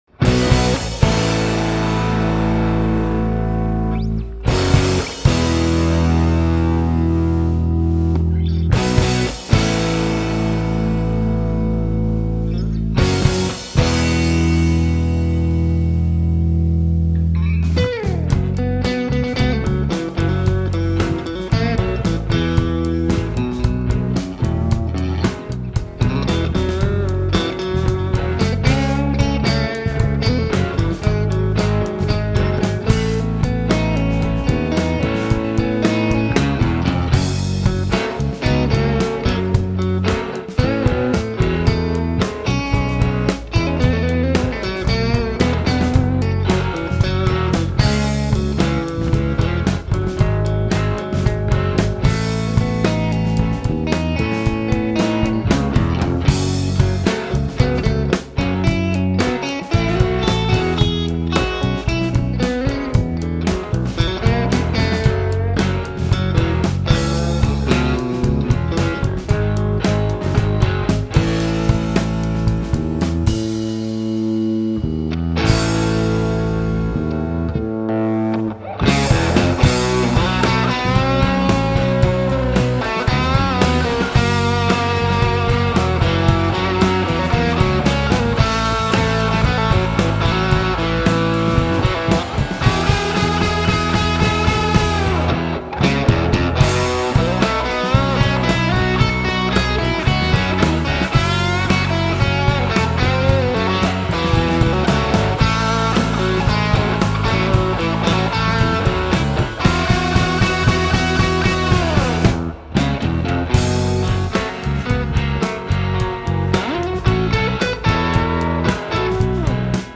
Working on a new White Volcano song … finished a mix of a rehearsal room recording from last month. I really enjoy that my self taught recording and mixing skills result in a such fun sounding demo track.